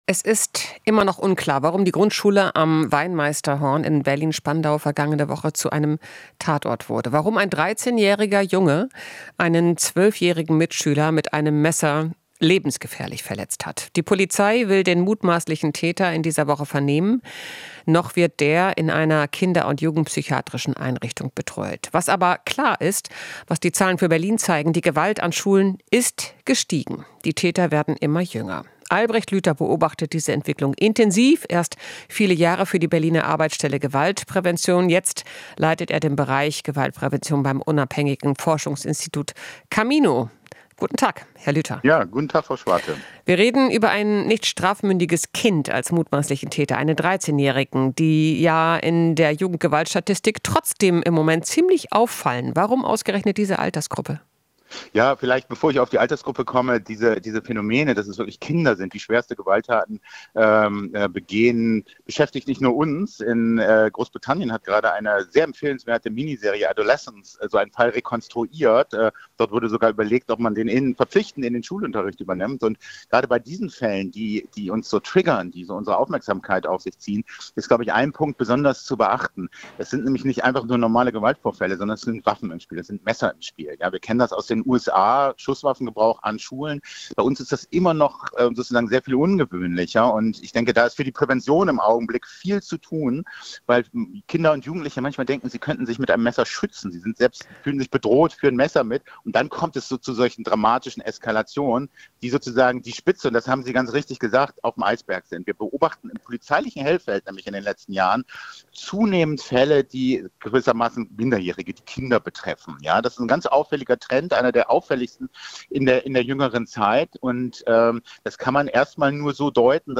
Interview - Experte zu Jugendgewalt: "Anzeichen einer Werteverschiebung"